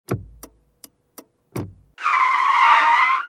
switch.ogg